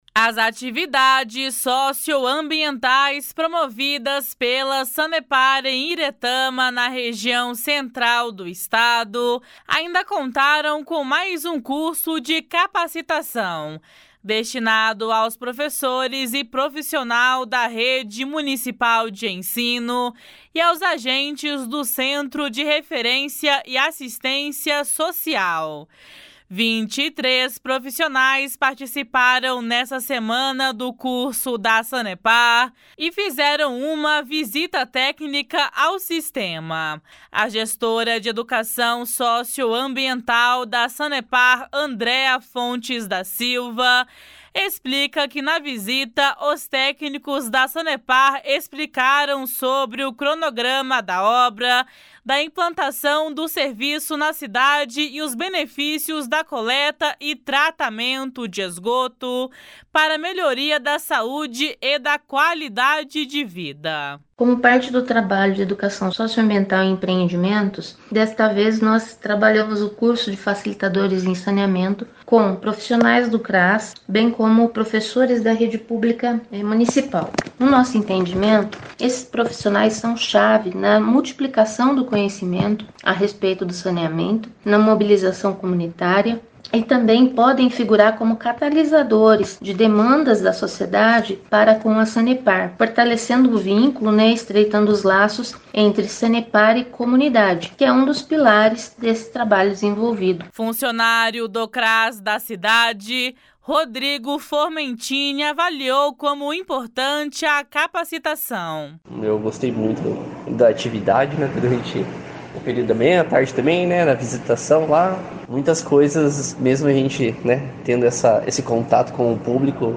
O treinamento dos profissionais de educação e de assistência social do município visa difundir a importância do esgotamento sanitário com orientação sobre a forma correta de utilização da rede coletora. (Repórter